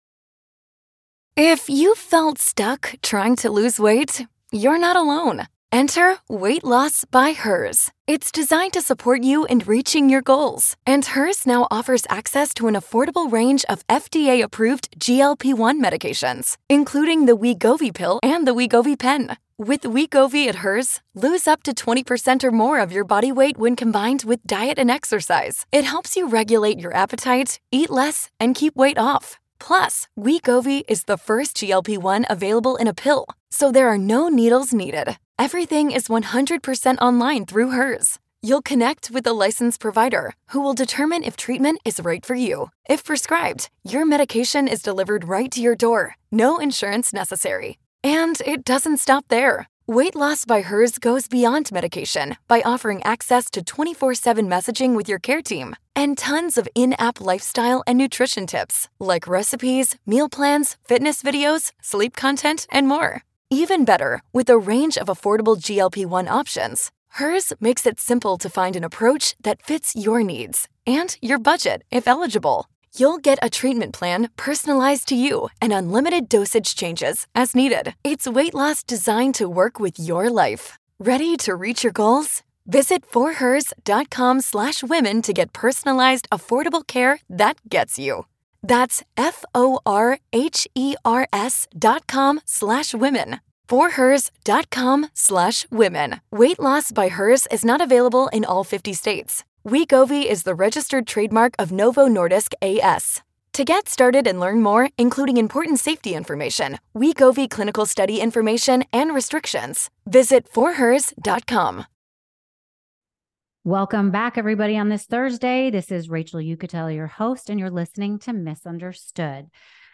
A.J. Benza is back with us to break down all the latest and greatest gossip and scandals from today's headlines.